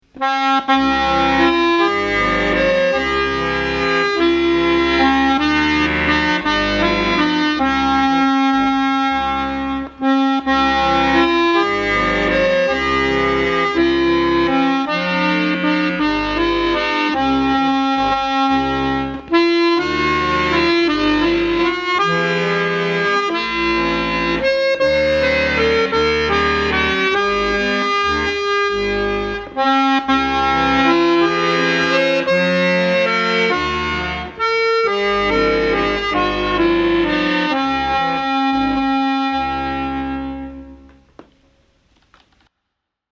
– Ich hoffe, meine Akkordeonbegleitung wird euch gefallen!